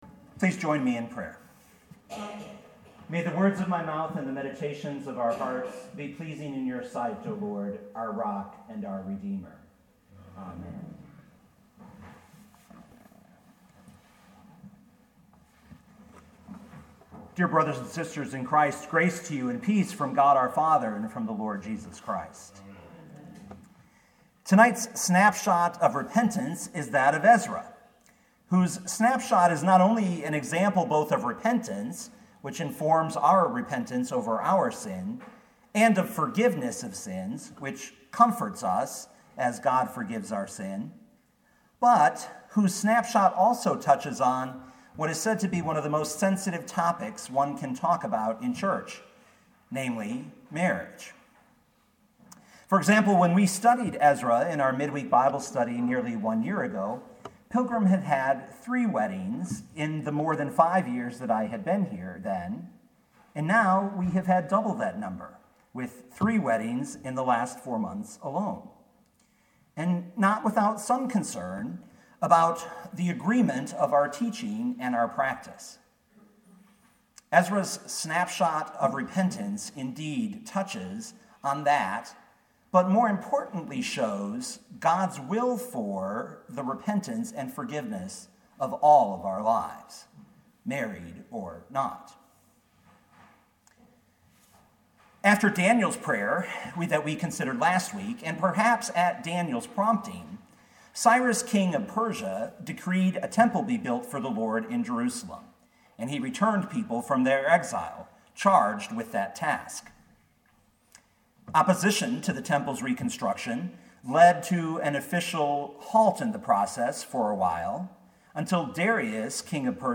2018 Ezra 9:1-10:17 Listen to the sermon with the player below